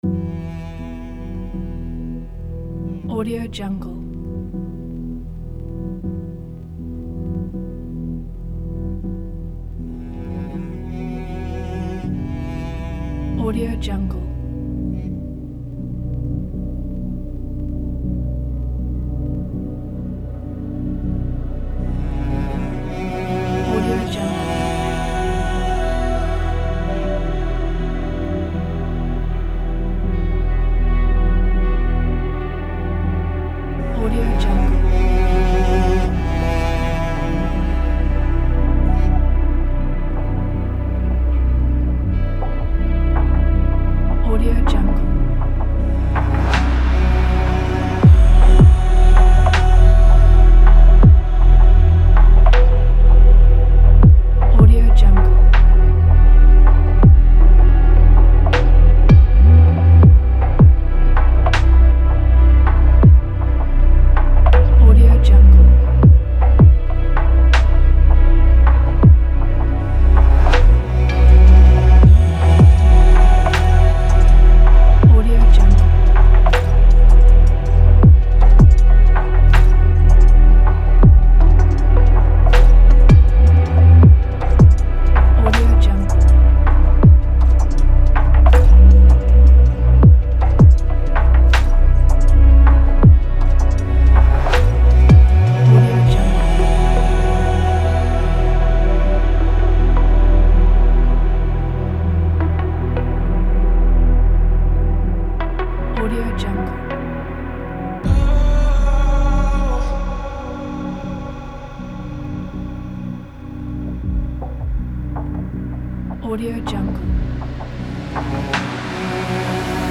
تجربی، آبستره